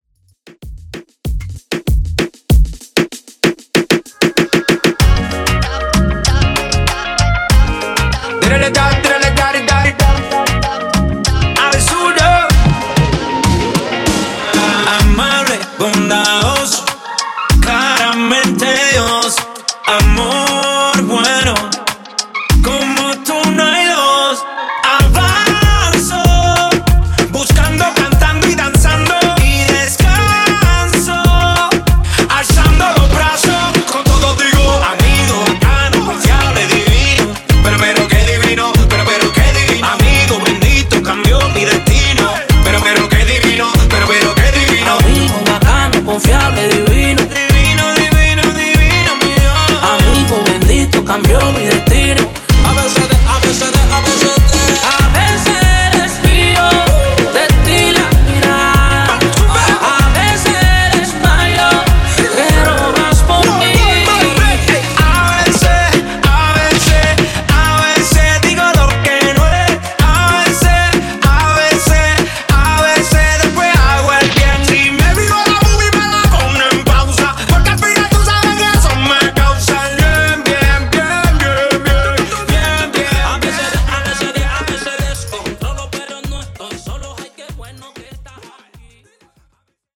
Genres: R & B , RE-DRUM , REGGAE
Clean BPM: 90 Time